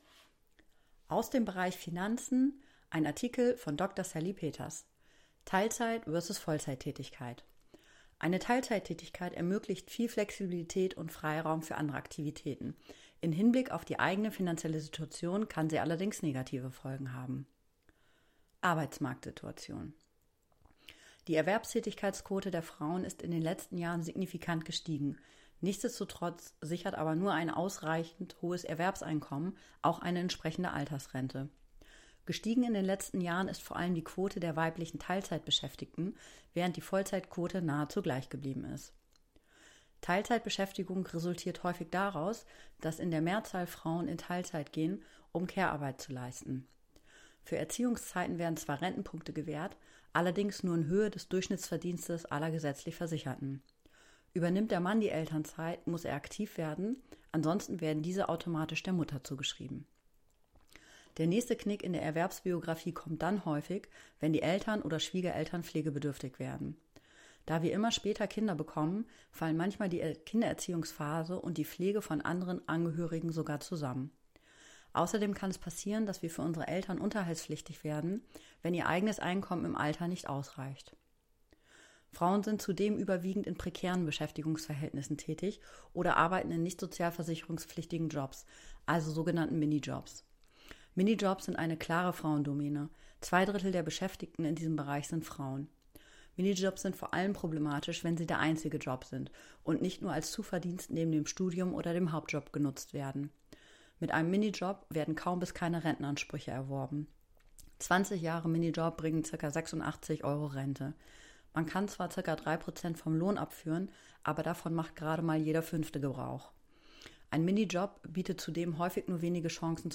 Unsere Vodcasts sind die optimale Lösung für alle mit wenig Zeit, hier die vertonte Version des Artikels hören.